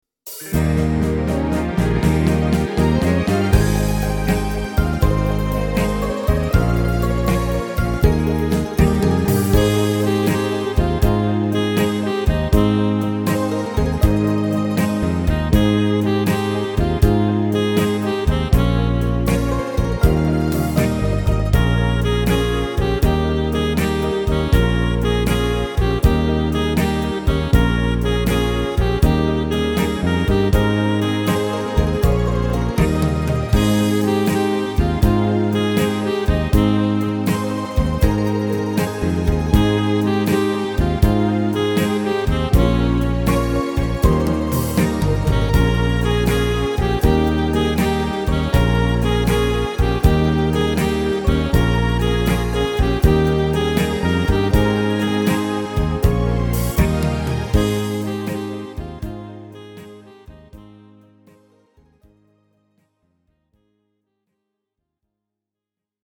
Tempo: 80 / Tonart: Ab / A / Bb – Dur
– 1 x MP3-Datei mit Melodie-Spur